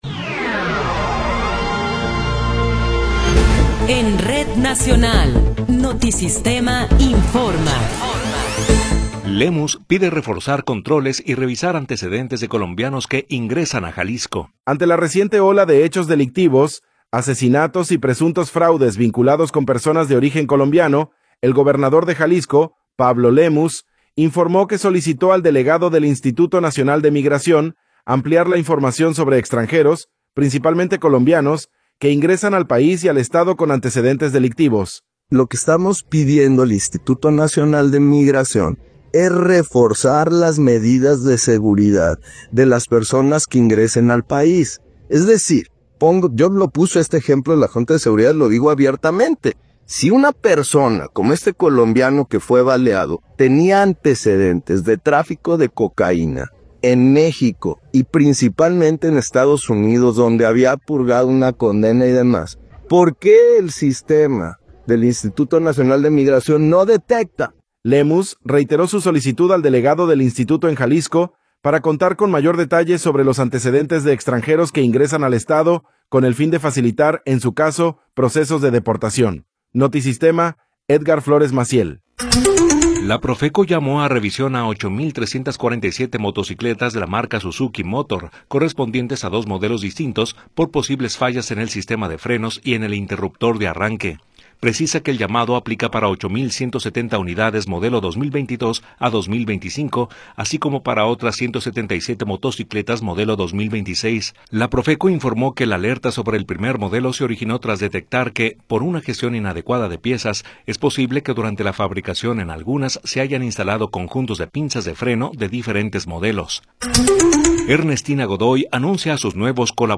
Noticiero 18 hrs. – 6 de Enero de 2026
Resumen informativo Notisistema, la mejor y más completa información cada hora en la hora.